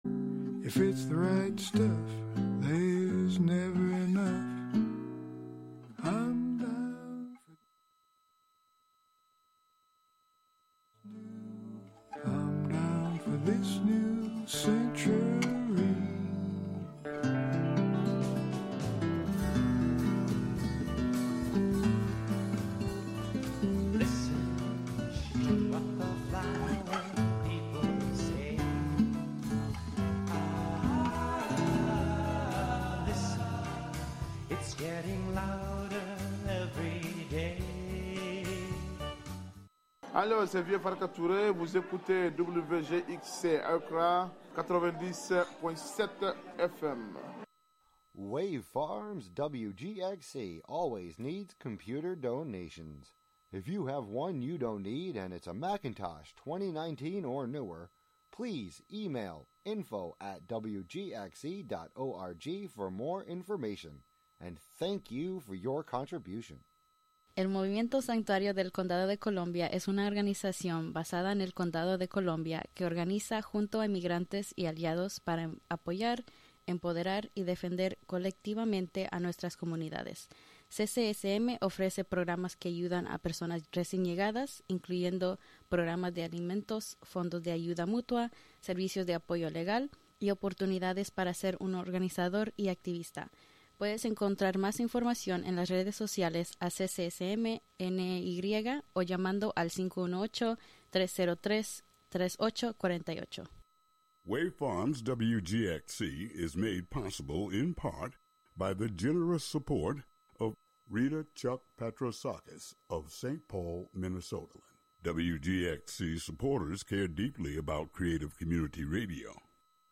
Be prepared to fly through various experiences on the air waves where you may hear any and everything...